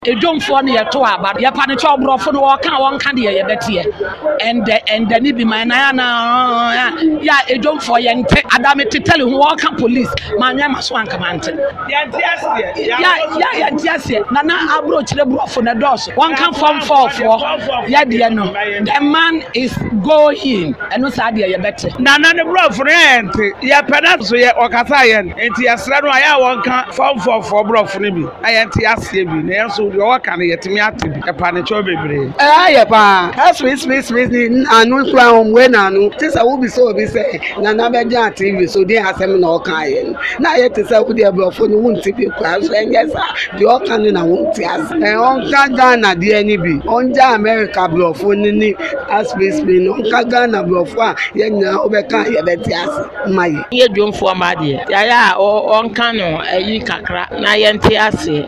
Listen to the traders